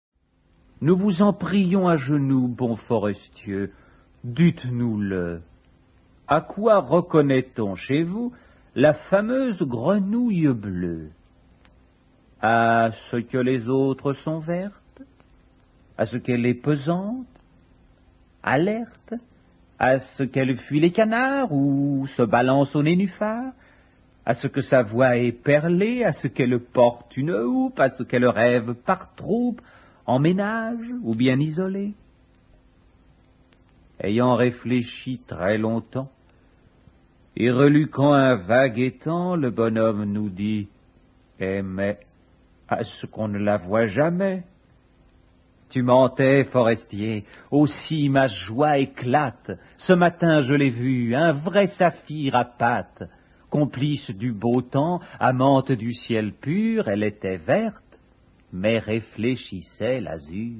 dit par Louis VELLE